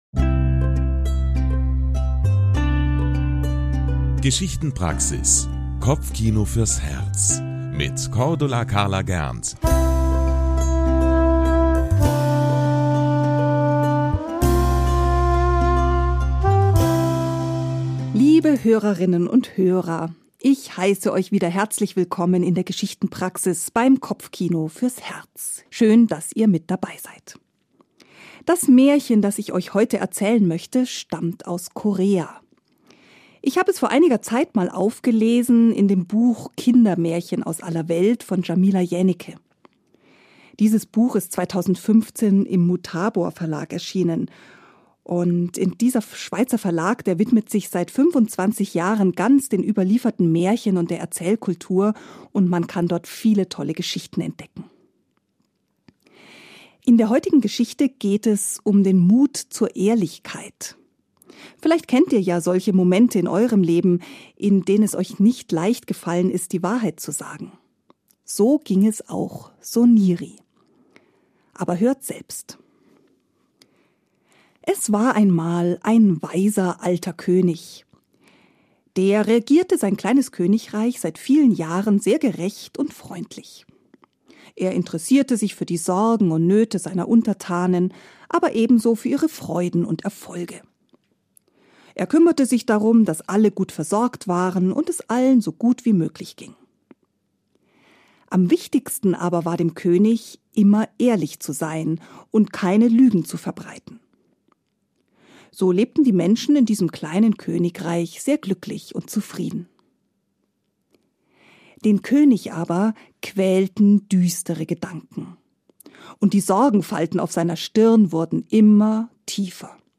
So ging es auch Soniri in dem koreanischen Märchen, das ich euch heute erzähle.